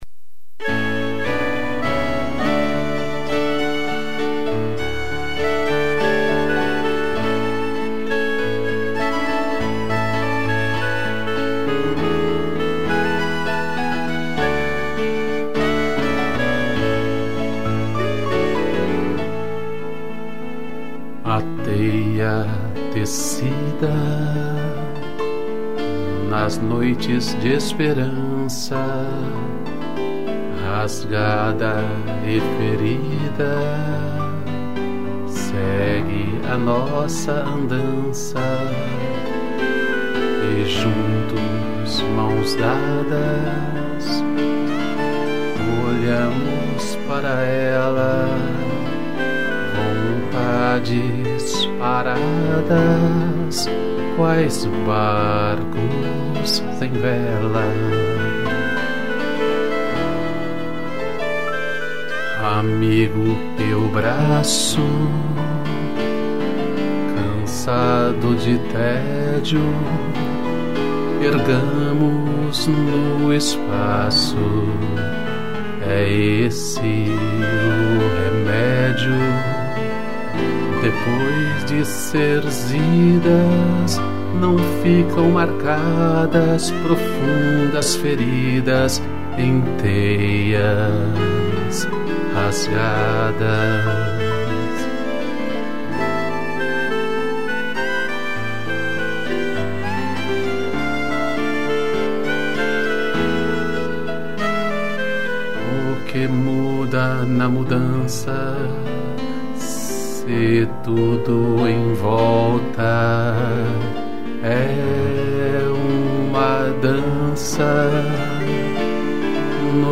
piano, clarineta e violino